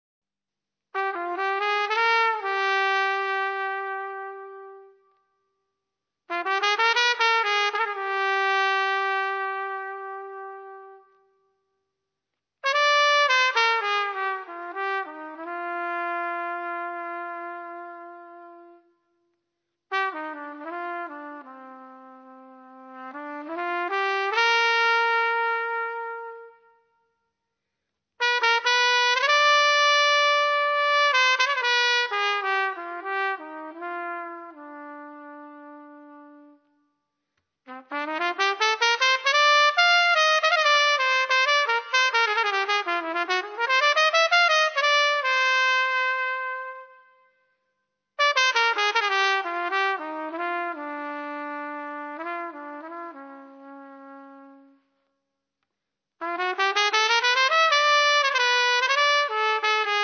tromba, flicorno soprano
sax soprano, contralto, tenore
piano
contrabbasso
batteria
ha un sapore prettamente spagnoleggiante.